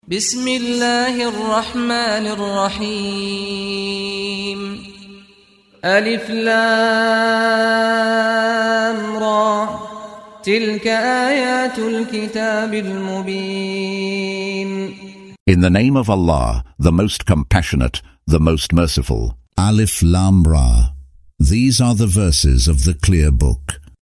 Audio version of Surah Yusuf ( Joseph ) in English, split into verses, preceded by the recitation of the reciter: Saad Al-Ghamdi.